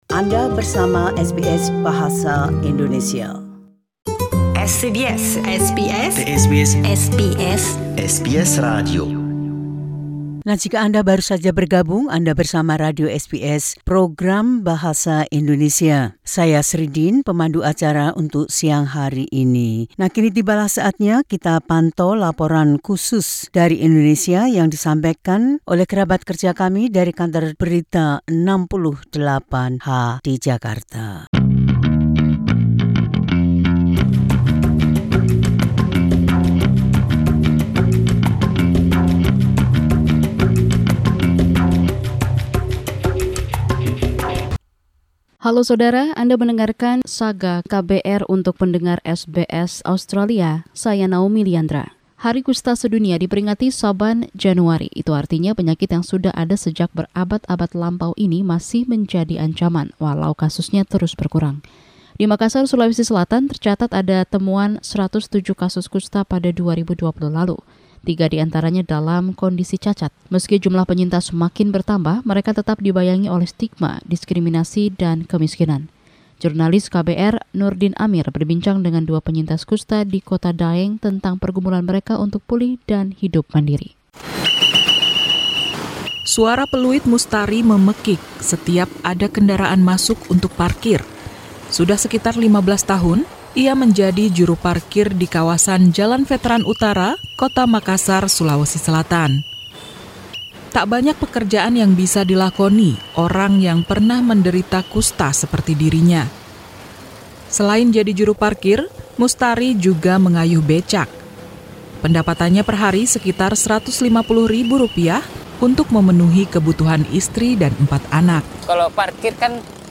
The team at KBR 68 H visited Makassar in Sulawesi to speak with people who have suffered the physical, social, economic and emotional impact of having leprosy in Indonesia.